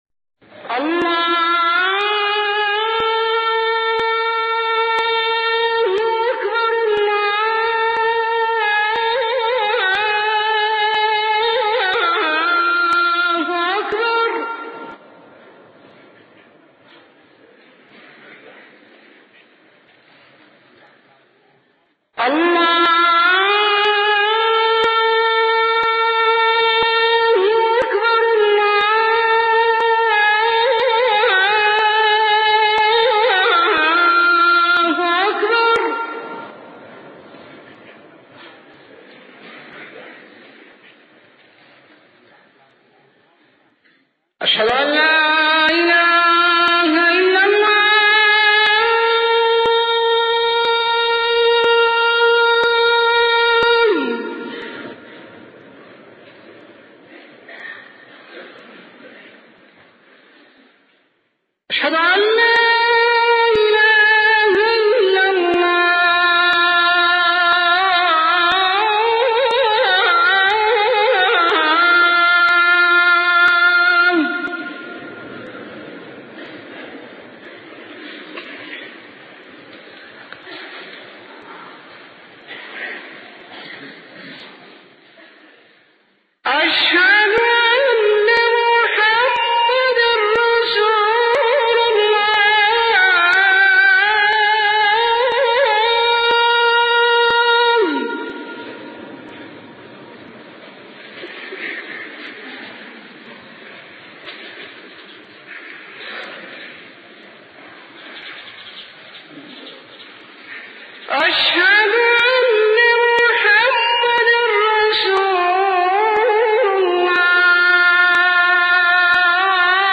أذان